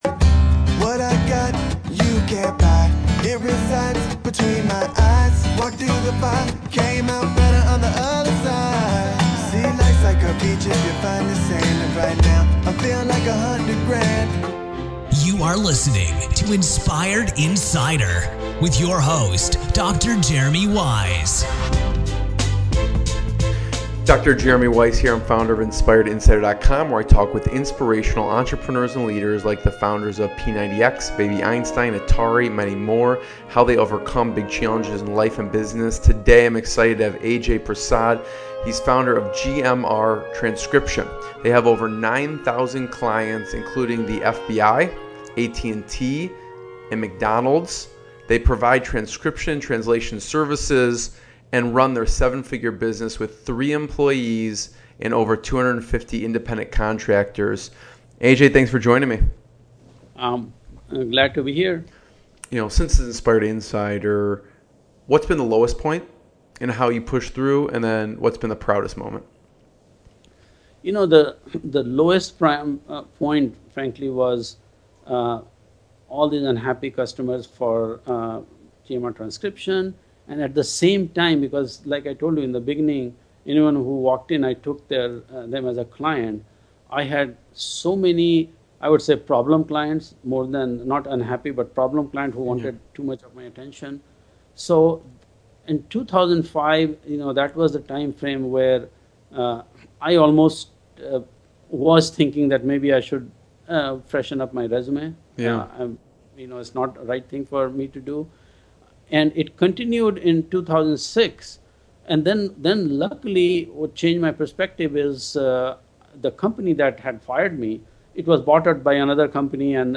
INspired INsider - Inspirational Business Interviews with Successful Entrepreneurs and Founders